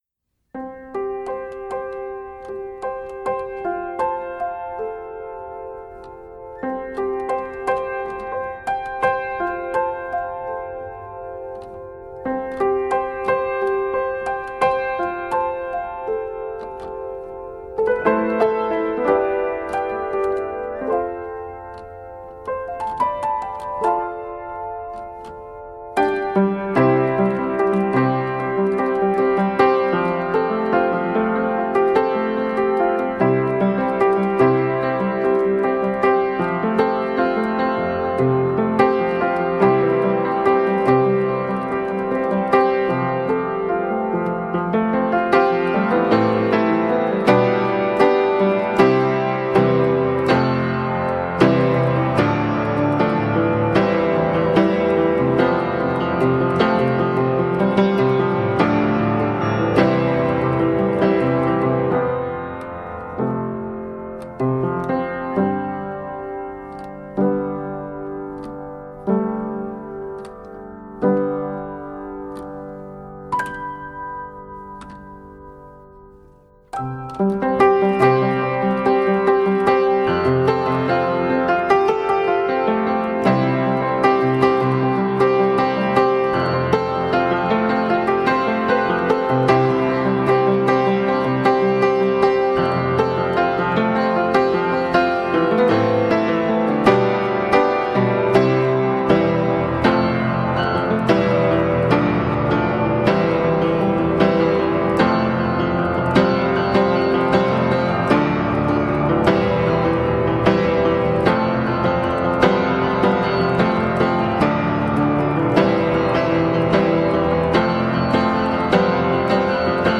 Instrumental
is an instrumental piece